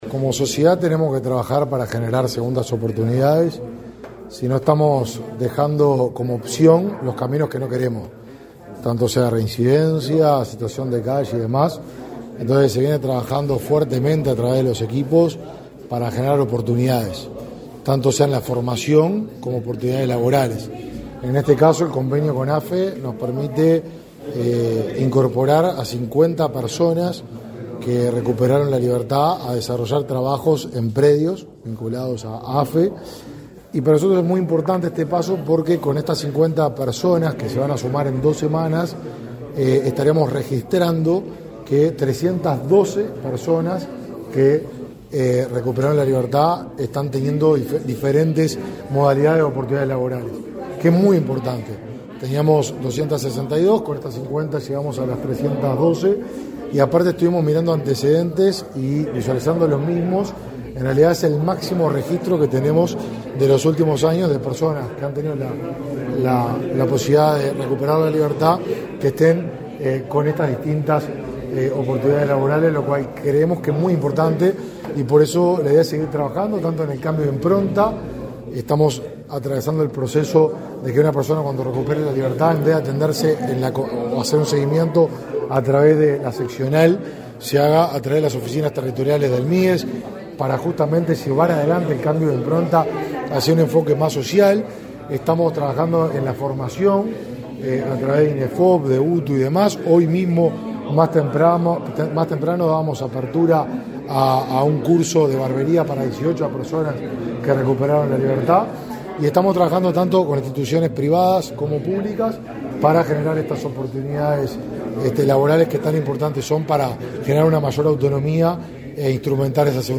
Declaraciones a la prensa del ministro de Desarrollo Social, Martín Lema
Tras participar en la firma de convenio entre el Ministerio de Desarrollo Social (Mides) y la Administración de Ferrocarriles del Estado para establecer prácticas laborales para egresados del sistema penitenciario de la Dirección Nacional de Apoyo al Liberado (Dinali), este 5 de setiembre, el ministro Martín Lema efectuó declaraciones a la prensa.
Lema prensa.mp3